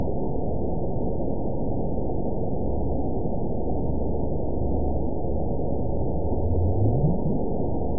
event 911403 date 02/25/22 time 03:04:20 GMT (3 years, 3 months ago) score 8.24 location TSS-AB01 detected by nrw target species NRW annotations +NRW Spectrogram: Frequency (kHz) vs. Time (s) audio not available .wav